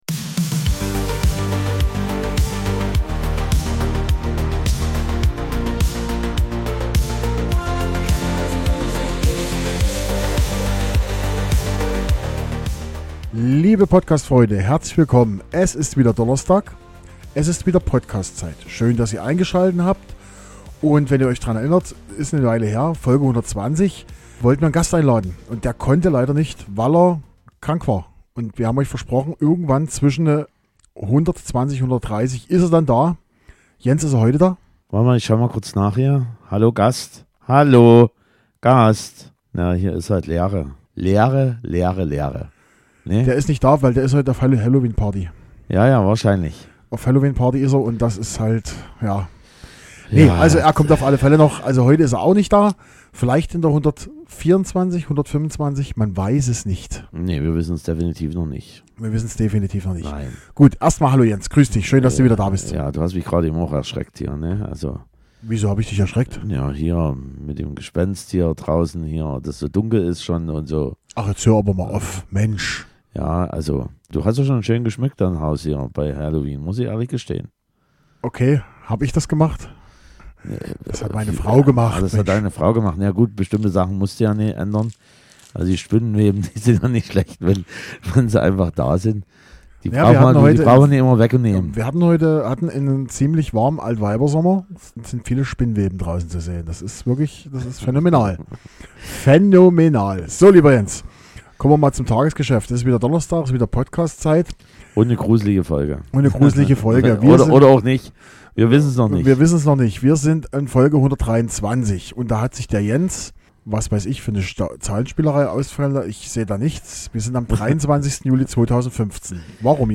Es ist vieles Housiges dabei, bzw. Titel, die sich diesem Genre bedienen.